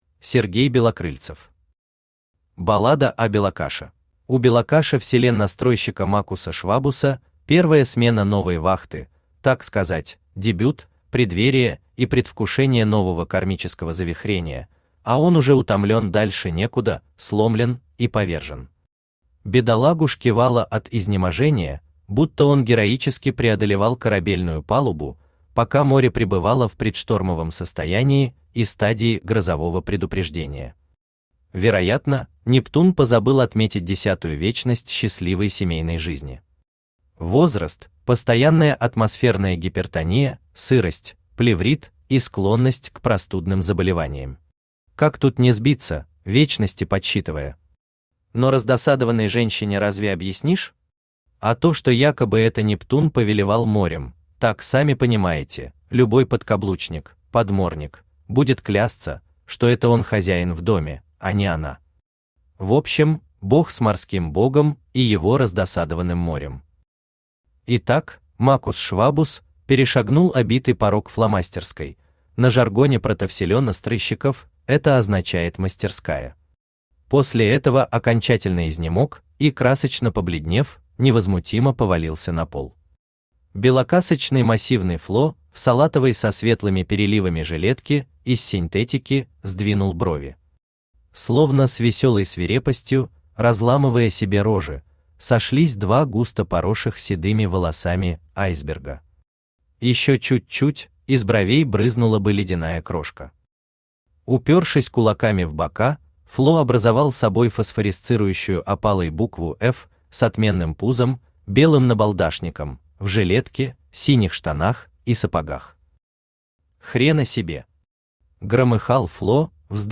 Аудиокнига Баллада о белокаше | Библиотека аудиокниг
Aудиокнига Баллада о белокаше Автор Сергей Валерьевич Белокрыльцев Читает аудиокнигу Авточтец ЛитРес.